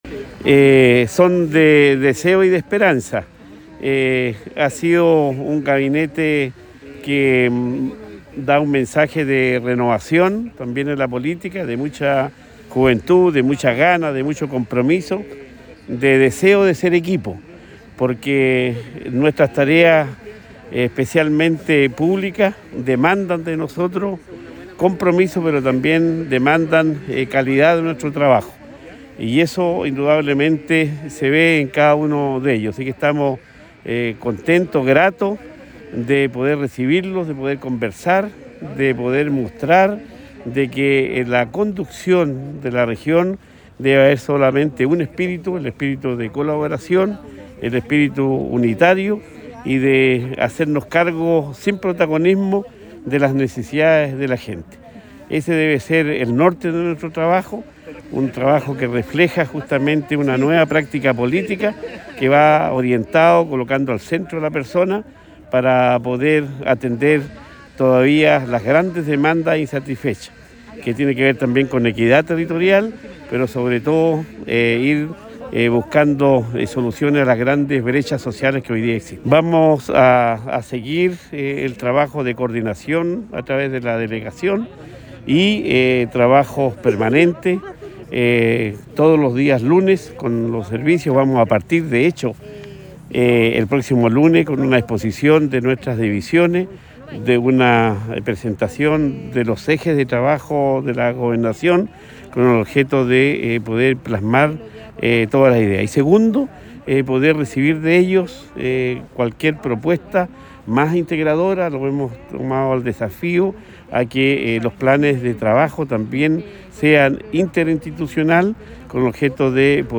Cuña_Gobernador-Regional_PRIMER-GABINETE-REGIONAL-.mp3